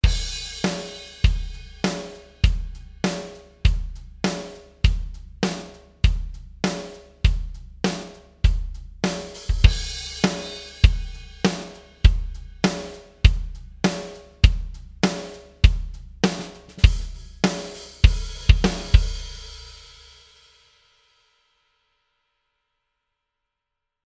Wir hören gleich einmal hinein und testen den Bomber mit Drum-Pattern aus Toontracks EZdrummer2, Modern Drum-Kit, Straight 4/4. FG-Bomber startet mit dem Preset „Drums Buss Fat“ in Takt 5.
Wie versprochen, sorgt FB-Bomber dafür, dass die Drums druckvoll und „fett“ rüberkommen. Dabei treten Bassdrum und Snare angenehm hervor, ohne dabei Hi-Hat und Becken zu beeinträchtigen.